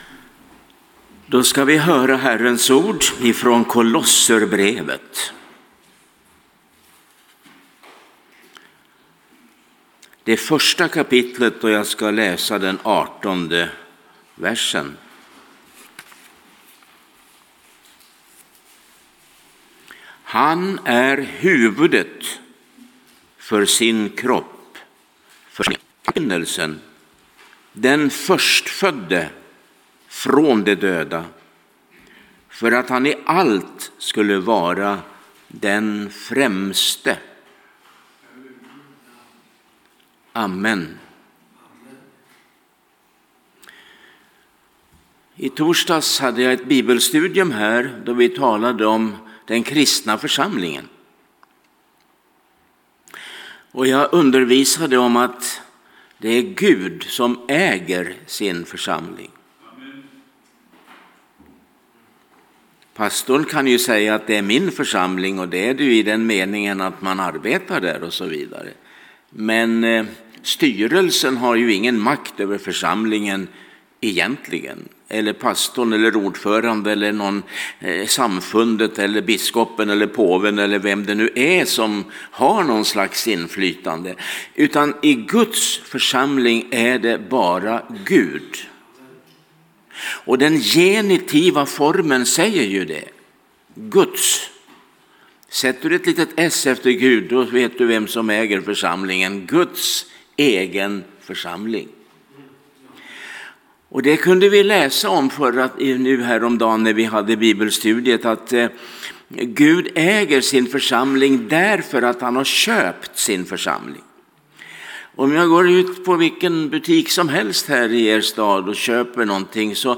Det finns ett par ikoner uppe till höger där du kan lyssna på ”bara” predikan genom att klicka på hörlurarna eller ladda ner den genom att klicka på pilen.